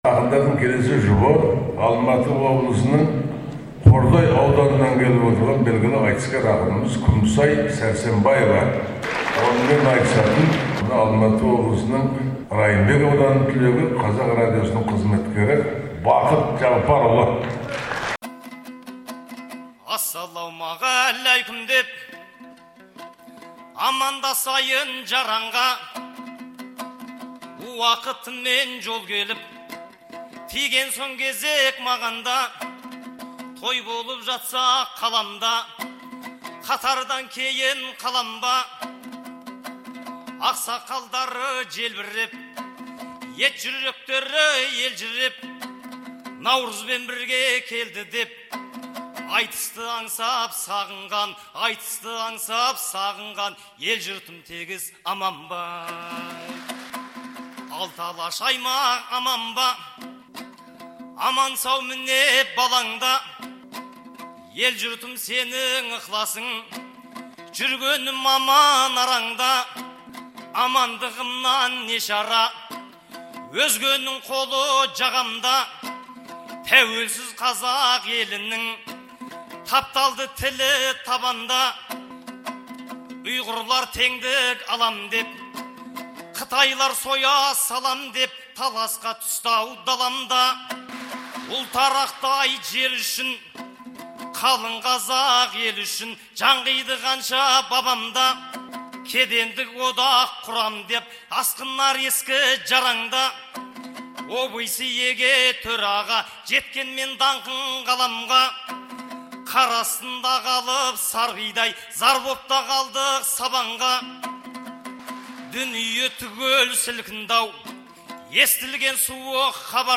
айтысын